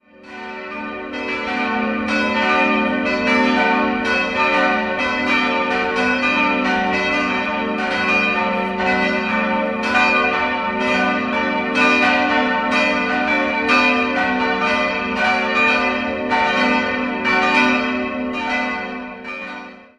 Idealquartett: fis'-a'-h'-d'' Die große Glocke wurde 1948 von Karl Hamm in Regensburg gegossen, die drei kleineren entstanden in der Gießerei Perner in Passau im Jahr 1966.